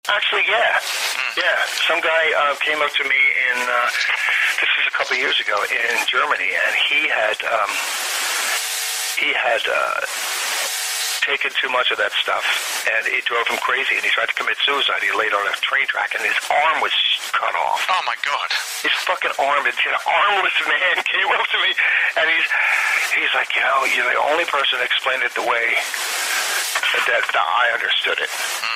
MONSTER Magnet’s Dave Wyndorf was one of the first people to appear on our podcast, White Line Fever.
Hear more of this unique 2009 interview, on demand, by hitting our PATREON PAGE where there is a veritable goldmine of such gems – all for just USD1.50 per month!